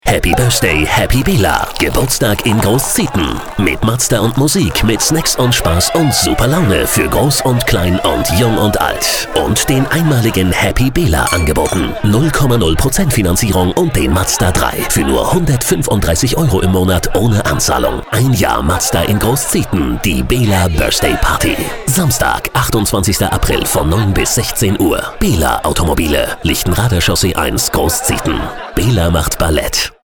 Radio: ALLE MAL HERHÖREN!